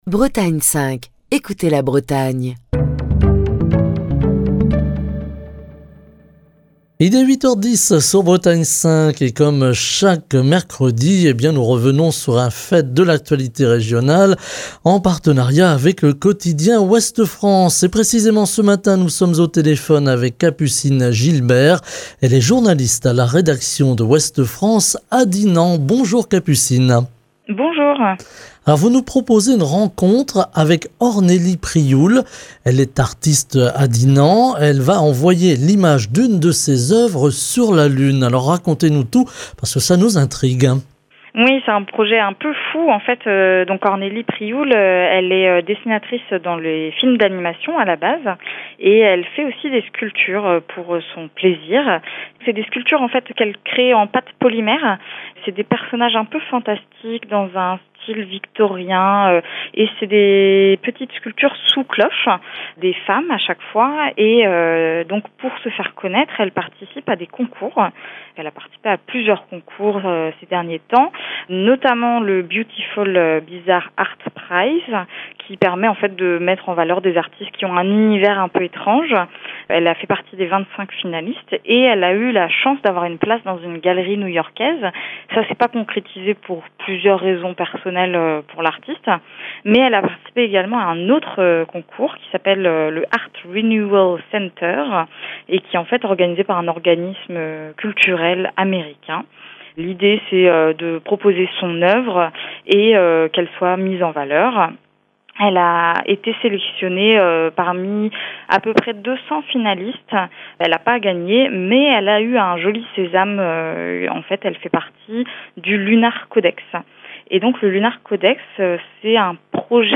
De Dinan à la lune... Aujourd'hui, nous détournons le titre du célèbre roman de Jules Verne pour vous raconter l'histoire d'une œuvre d'art qui va rejoindre notre satellite naturel. Ce matin, nous sommes au téléphone avec